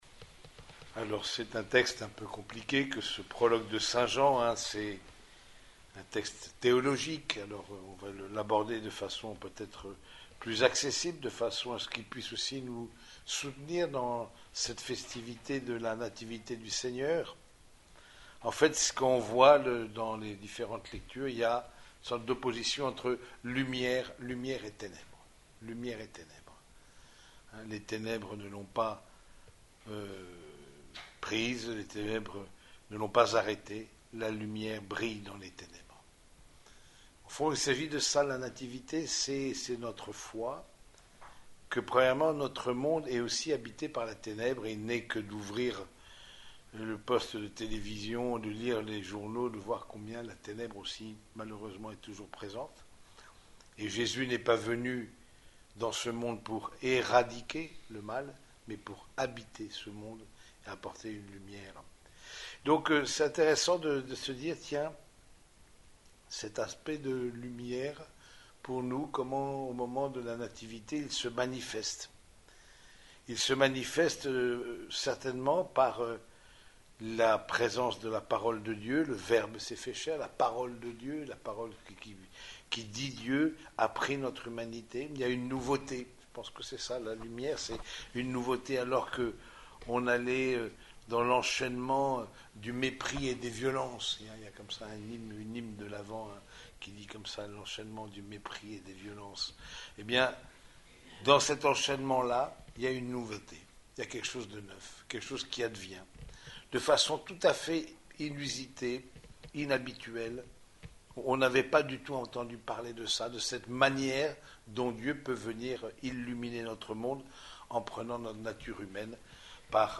Homélie de la solennité de la Nativité du Seigneur (messe du jour)
Cette homélie a été prononcée au cours de la messe dominicale célébrée dans la chapelle des sœurs franciscaines de Compiègne.